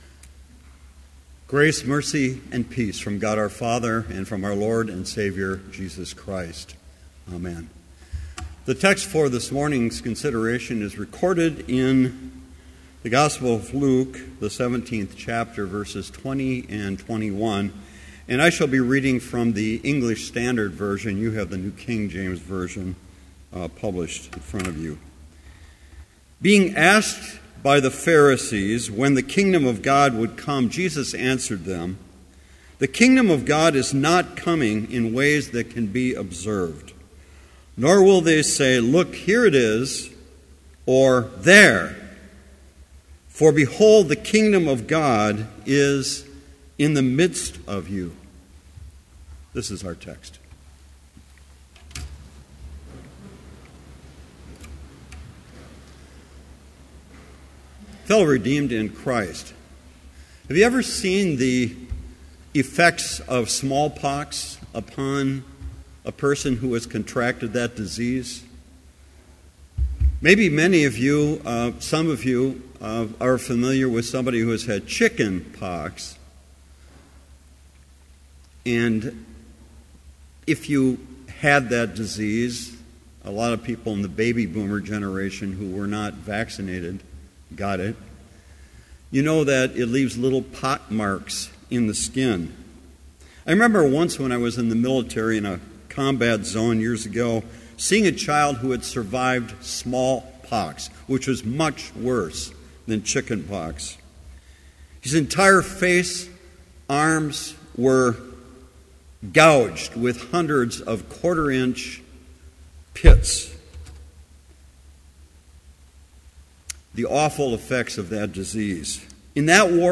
Complete service audio for Chapel - December 10, 2014